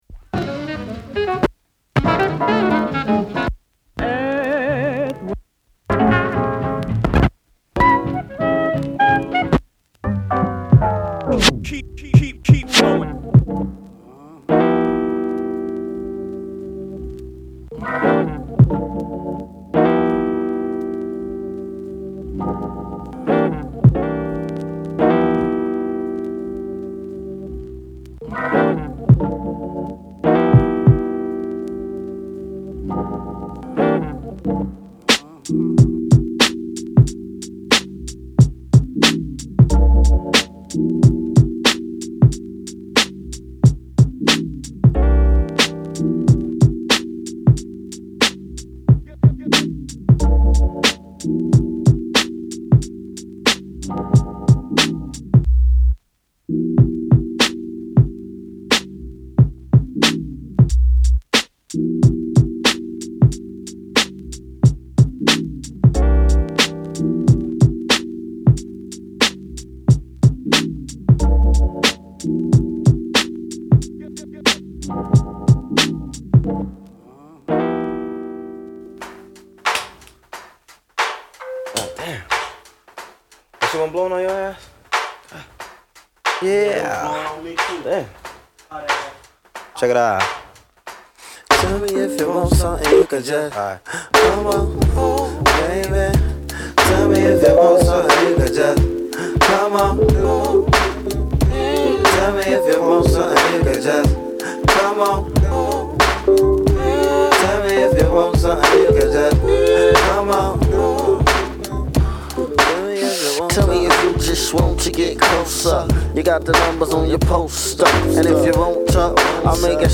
| ハーコーB-BOYのデートに、ユルいドライブのお供に、深く吸い込んだユルい時間に役立つCHILL MIXです。
MellowなHip Hop～Nu Soul～Rare Grooveを中心にセレクトし、グルーヴィーにミックスした1枚。
＊試聴はダイジェストです。